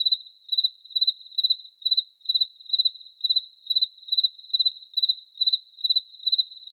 insectnight_10.ogg